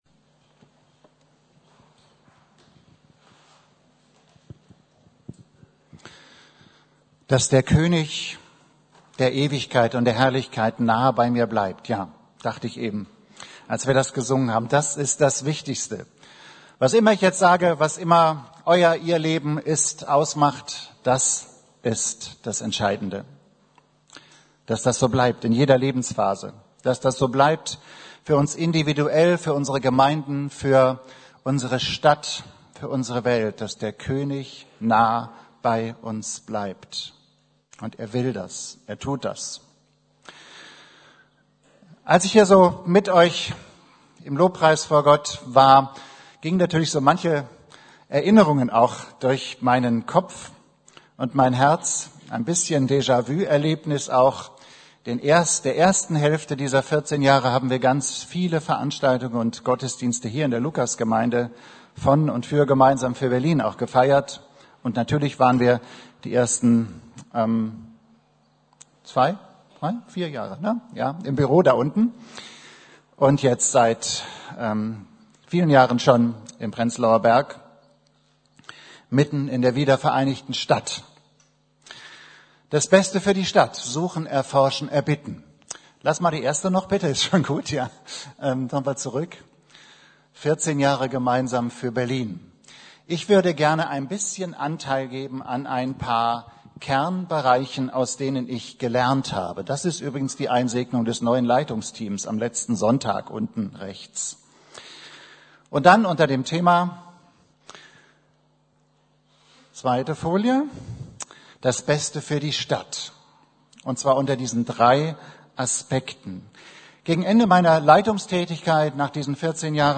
Das Beste für die Stadt - suchen, erforschen, erbitten ~ Predigten der LUKAS GEMEINDE Podcast